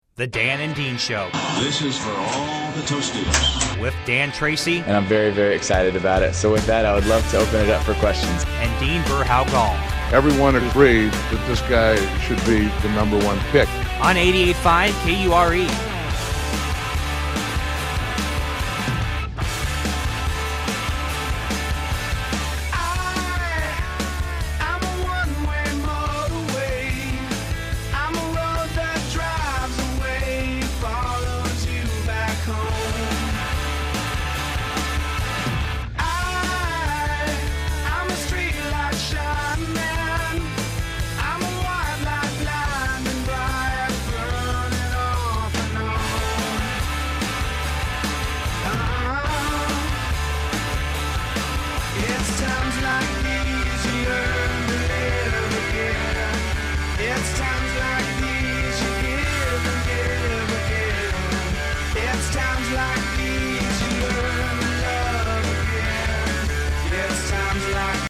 Category: Radio   Right: Personal